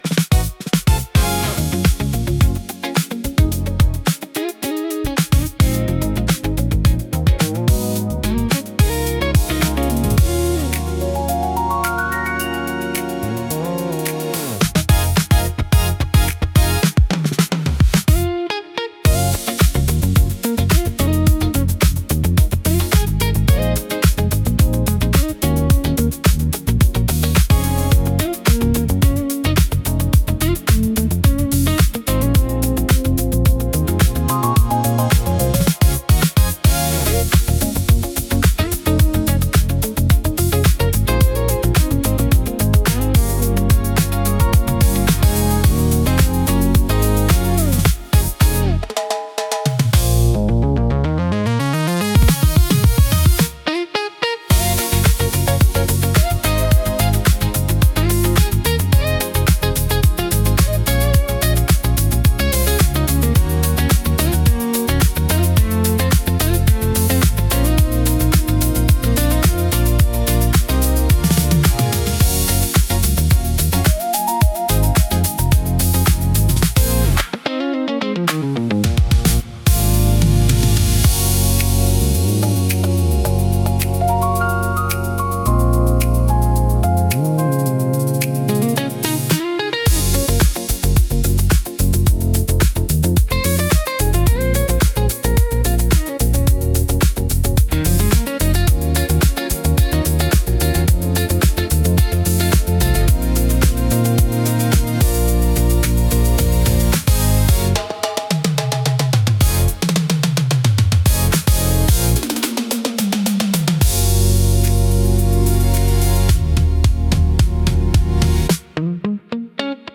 イメージ：インスト,シティーポップ,ジャズ・フュージョン,シンセ・ポップ
インストゥルメンタル（instrumental）